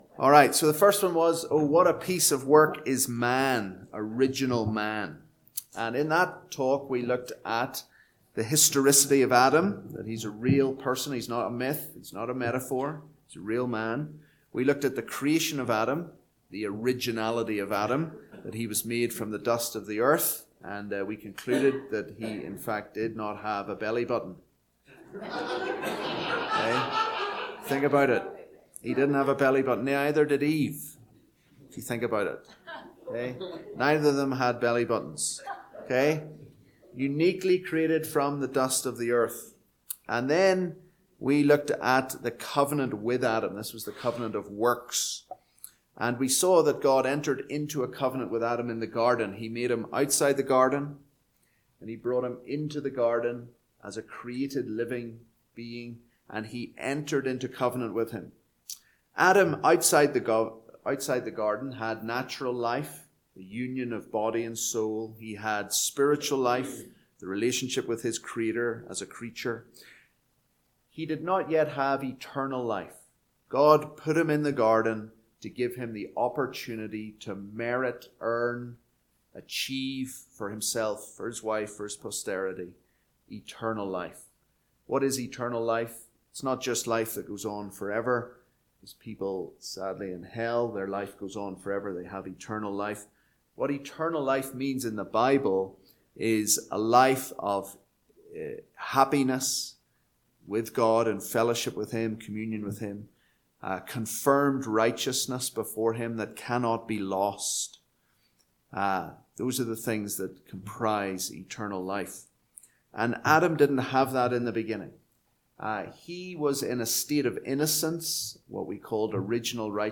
Due to a technical issue, we did not get a recording of Session 1. This is an audio summary of the first session.